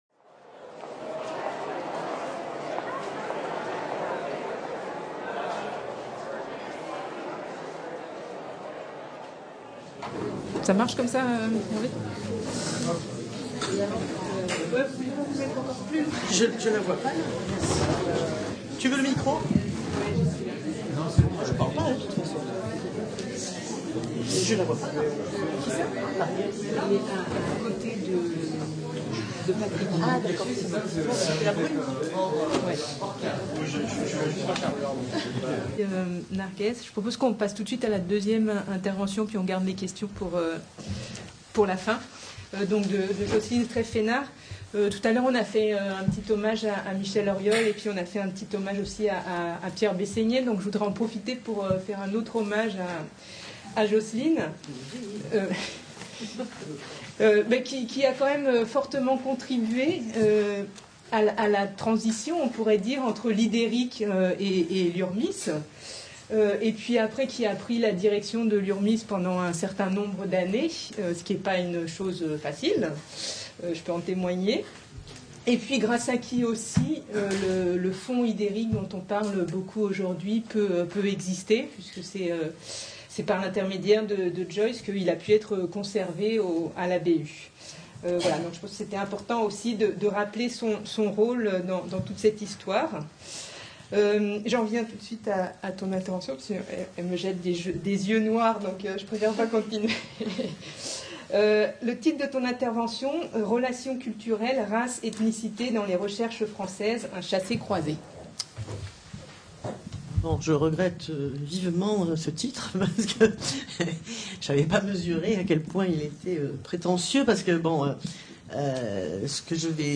un chassé-croisé Colloque du cinquantenaire de la création du CERIN, Centre d’études des relations interethniques/ Ideric, Institut d’études et de recherches interethniques et interculturelles 3 et 4 mai 2017, MSHS, Université Nice Sophia Antipolis http